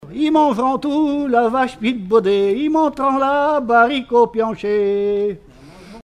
circonstance : bachique
Regroupement de chanteurs du canton
Pièce musicale inédite